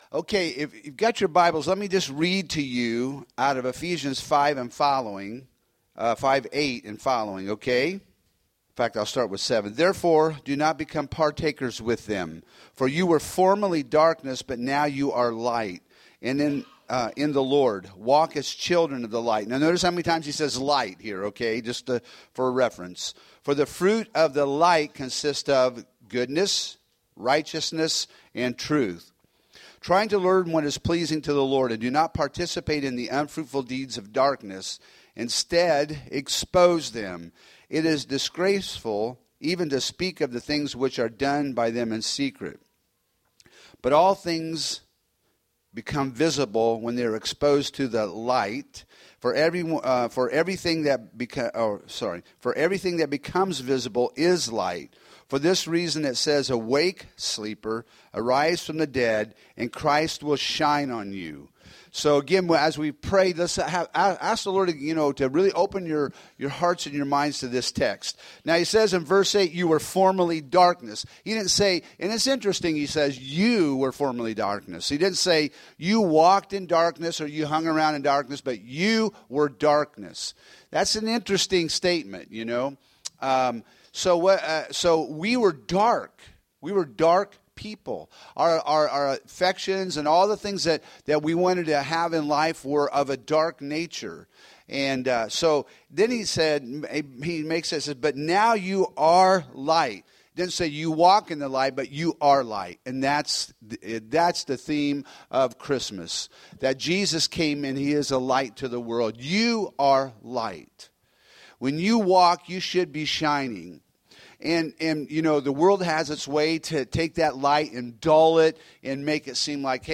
Home › Sermons › Ephesians 5:8~20